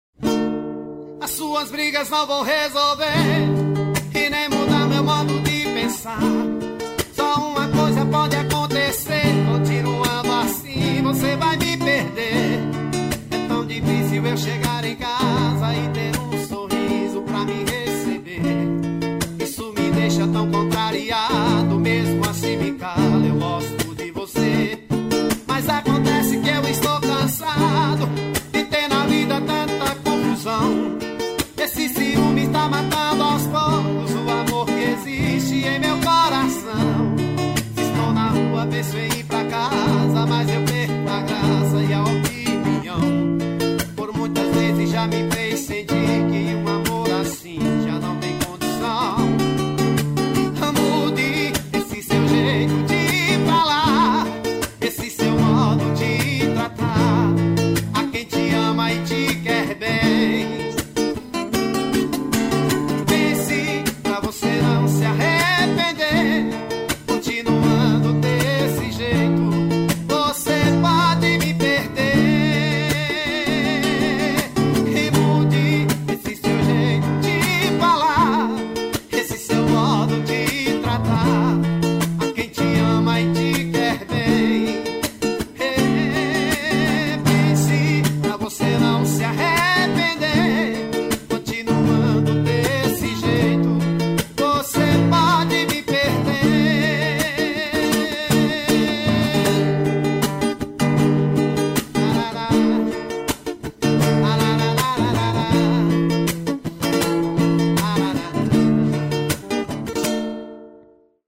ao vivo voz e violão